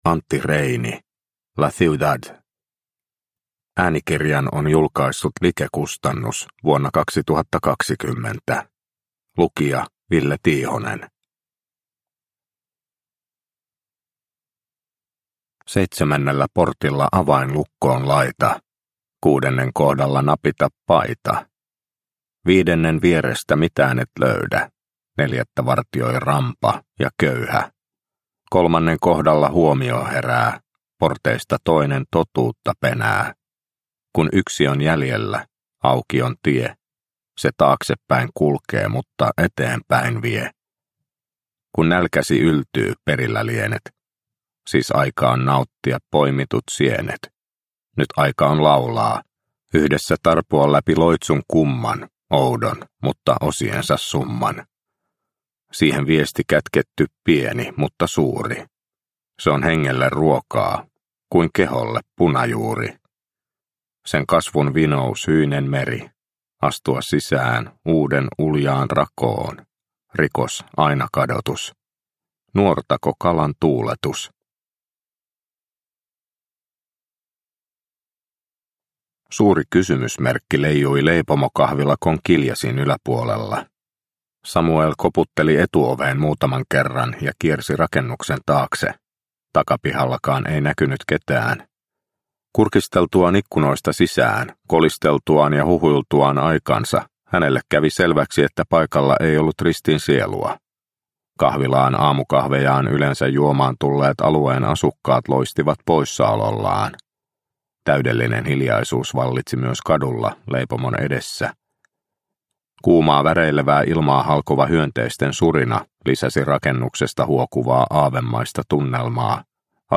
La Ciudad – Ljudbok – Laddas ner